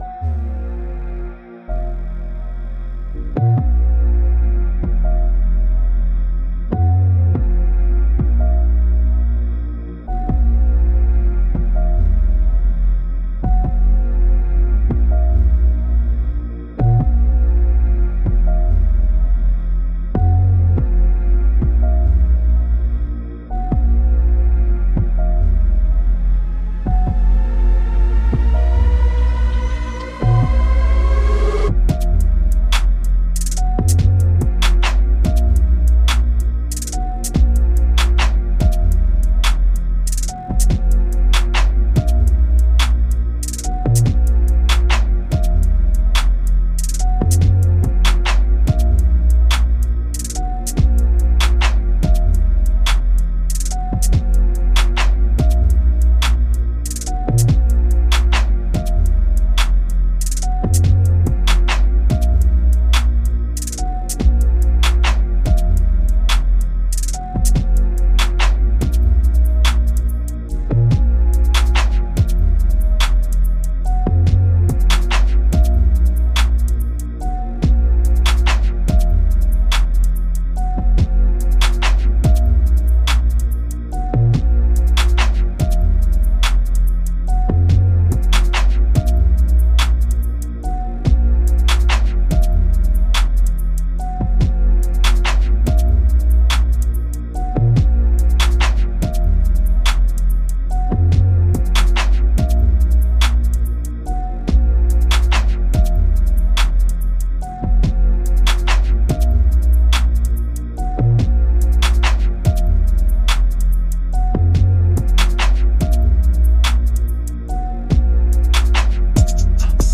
بیت دریل گنگ رپ